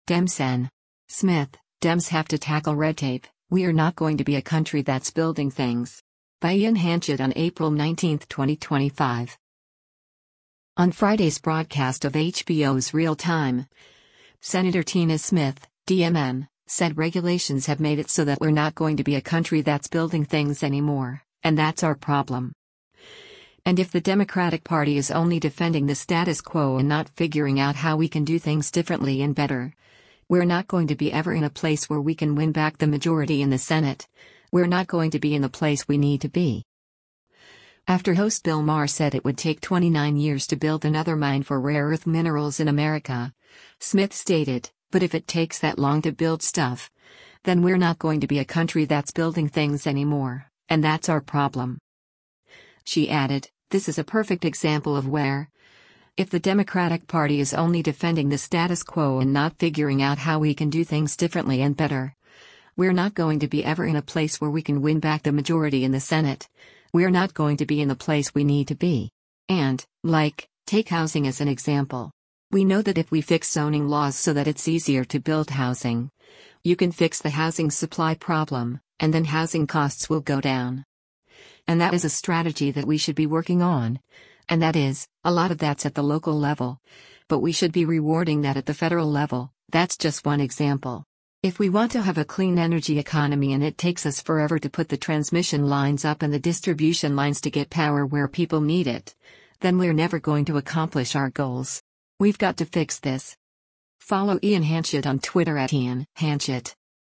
On Friday’s broadcast of HBO’s “Real Time,” Sen. Tina Smith (D-MN) said regulations have made it so that “we’re not going to be a country that’s building things anymore, and that’s our problem.”